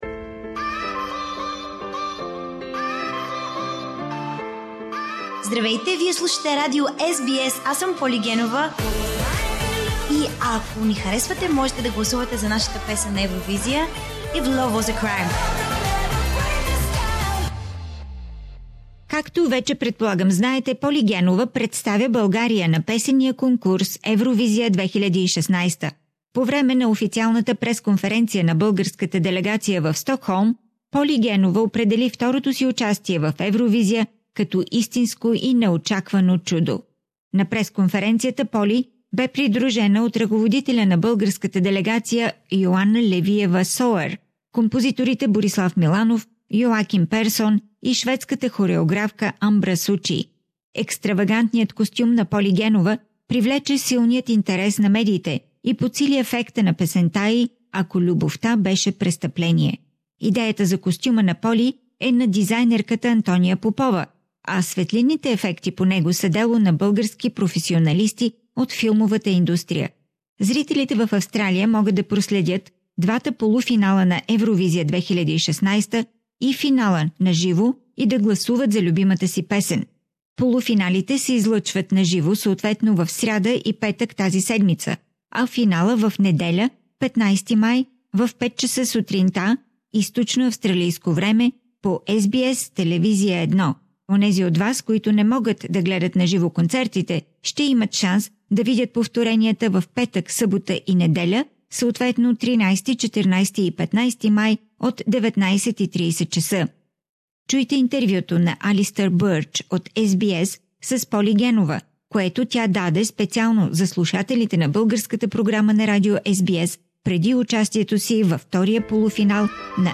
Поли Генова - ексклузивно интервю за българите в Австралия